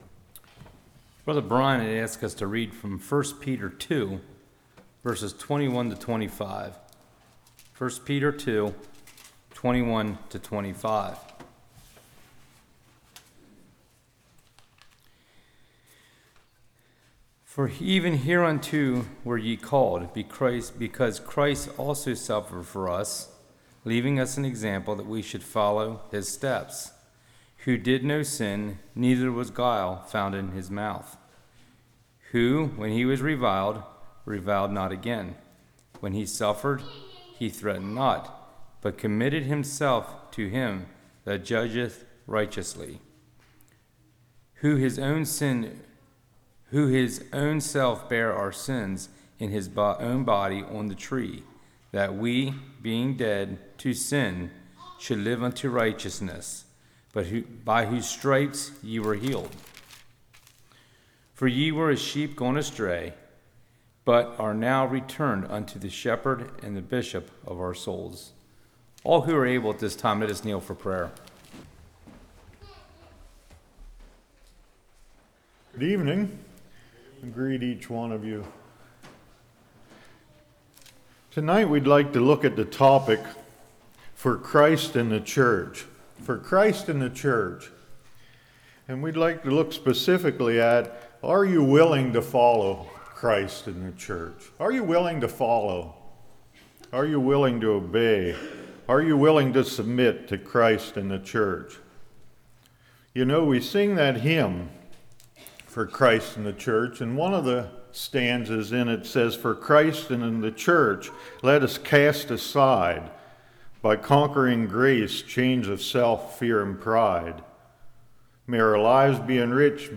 Passage: 1 Peter 2:21-25 Service Type: Evening Am I a Good Follower?